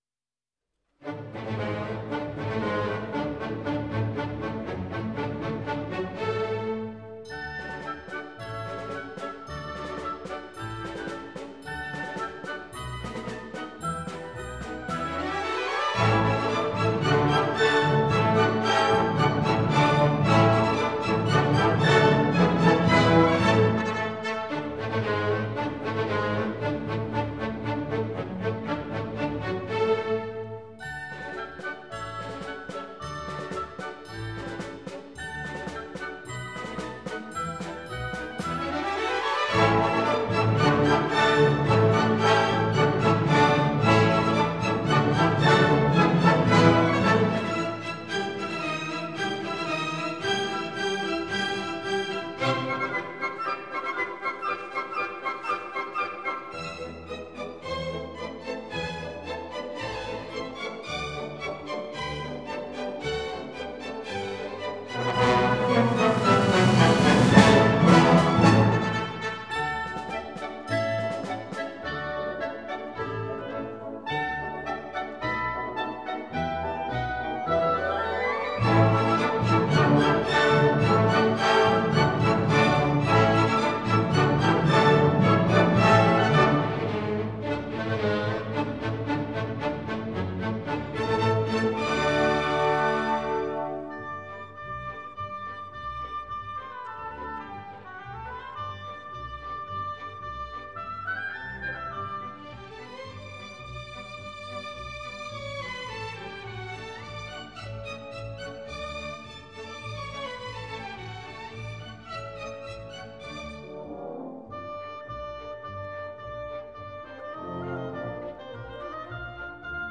进行曲 March Band